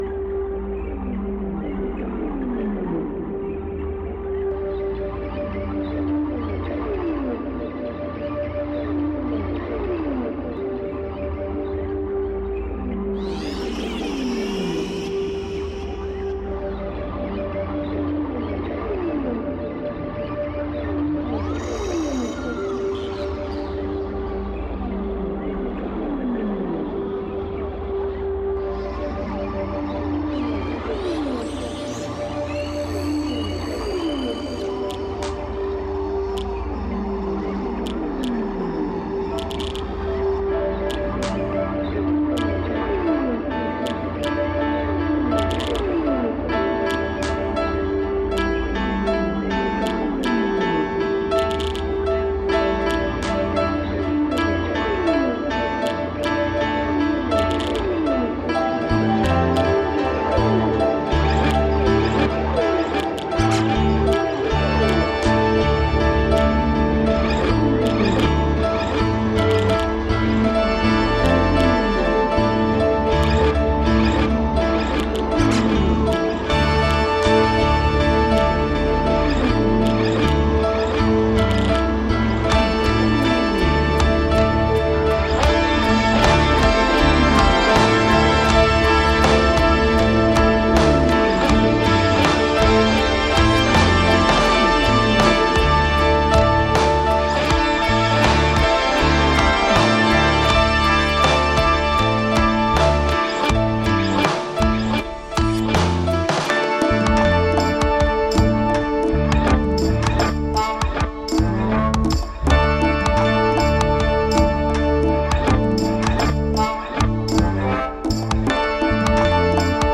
Ambient Trance Prog aus Offenbach aM